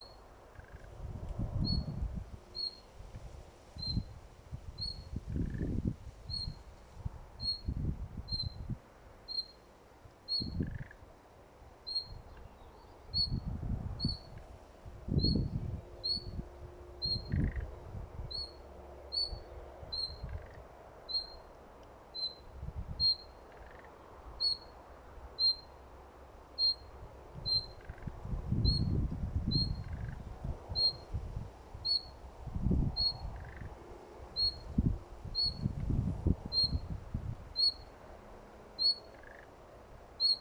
соловей, Luscinia luscinia
СтатусВзволнованное поведение или крики
ПримечанияLigzdo blakus dārzā. Ar uztraukuma saucieniem pavadīja dārzā ieklīdušu kaķi. Pirmo reizi lakstīgalas uztraukuma saucienos, starp svilpieniem dzirdēju 'tarkšķi'.